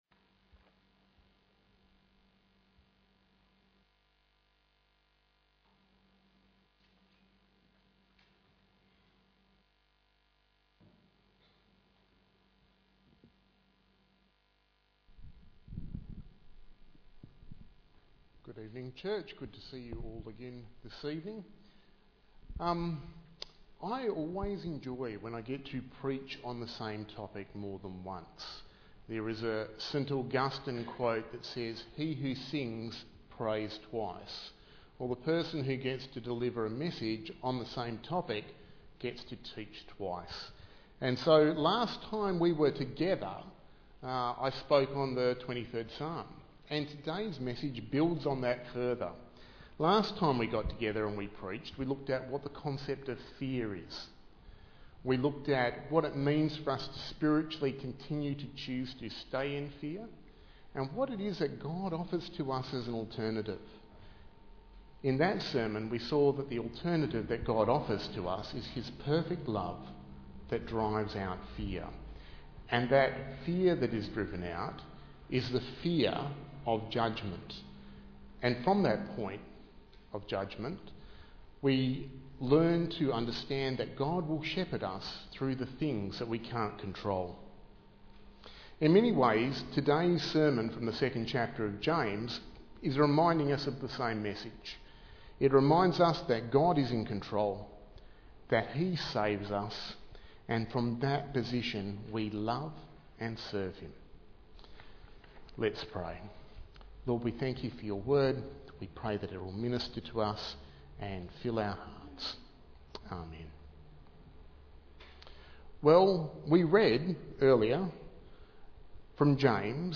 Bible Text: James 2:14-26 & 1 John 4:7-21 | Preacher